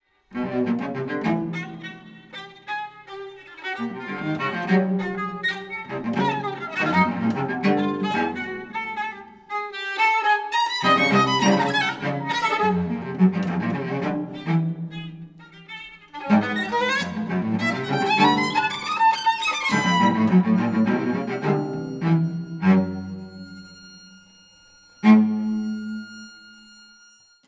Description:Classical; chamber music
Instrumentation:Violin, violoncello